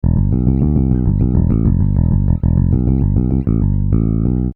I decided to create free to use, uncopyrighted, studio-quality music loops that you can use whenever, wherever, and however you choose and please!
Funk Loops
FingerstyleBassLoop
Tempo: 200
Key: E minor
Time Signature: 3/4